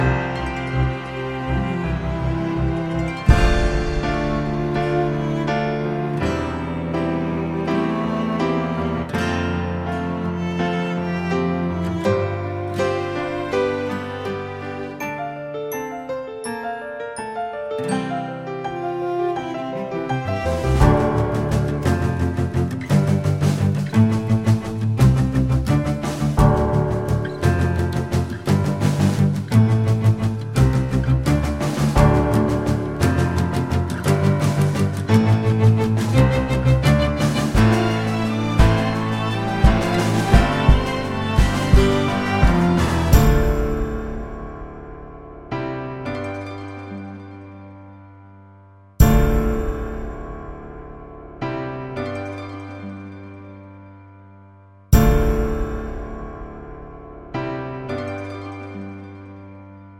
no Backing Vocals Musicals 2:31 Buy £1.50